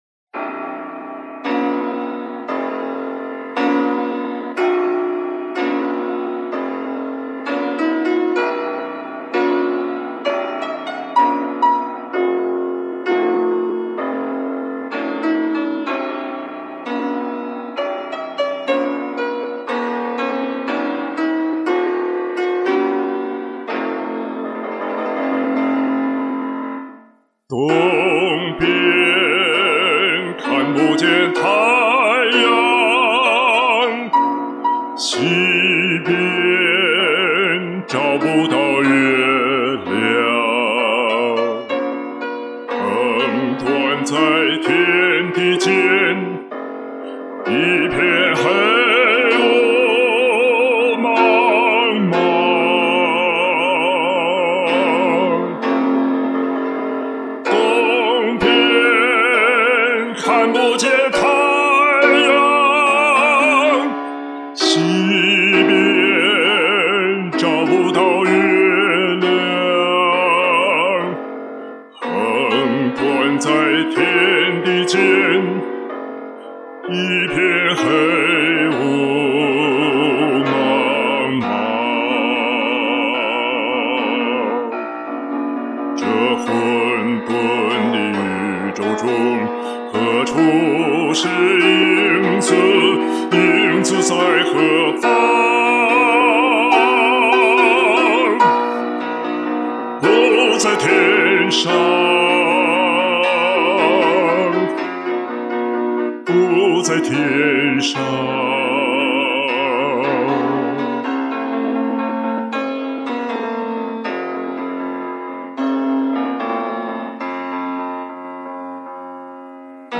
最高音刚好处于男中音换声曲，使太阳两个字很叫劲儿。过了这个坎儿之后心情与旋律一样渐渐走向自信和开朗